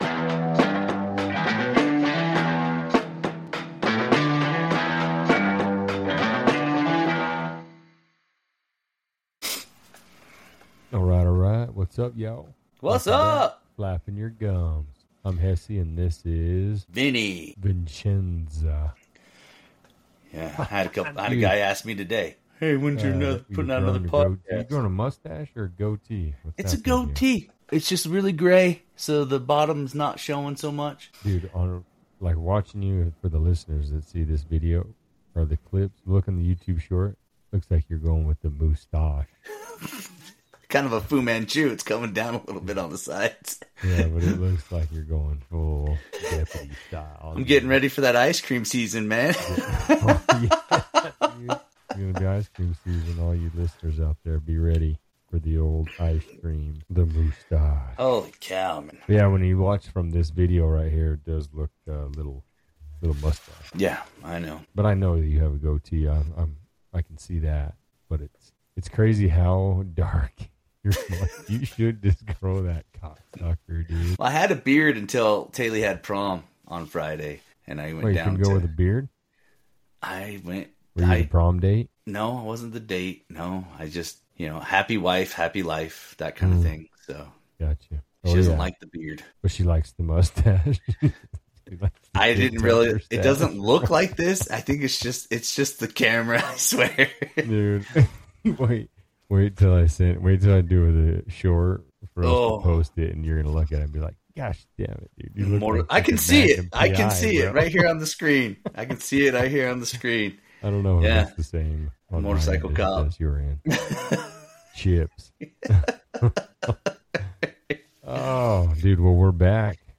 Couple of brothers just hanging out chatting it up about life.